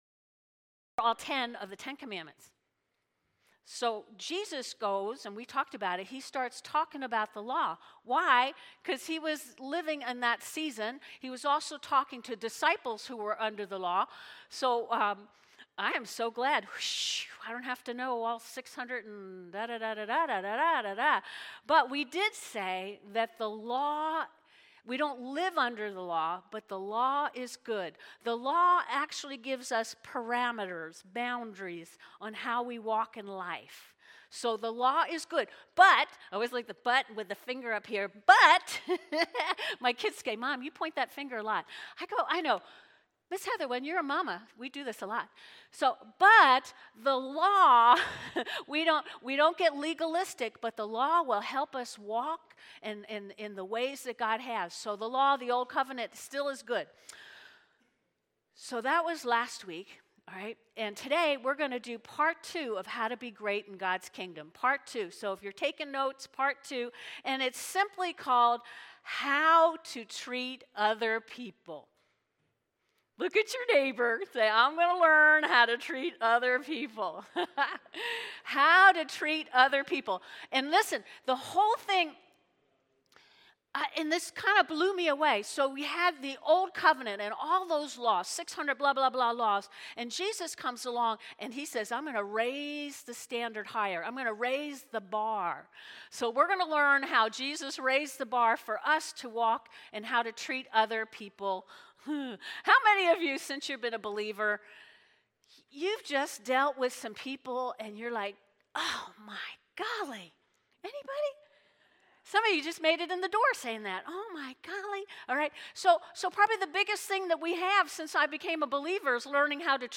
Sermon on the Mound Part 7: How We Treat Others | York Christian Fellowship
Sunday AM Service